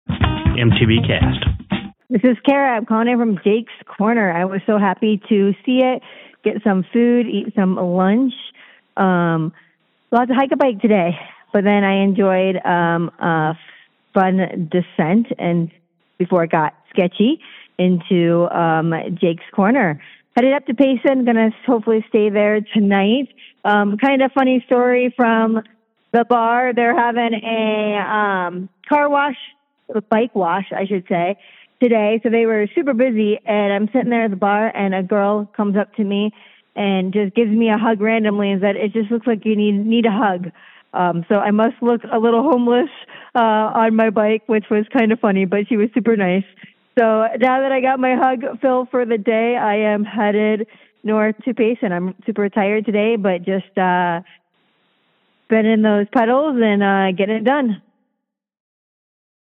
Posted in AZT25 , Calls Tagged AZT25 , bikepacking , Call Ins , calls , endurance racing permalink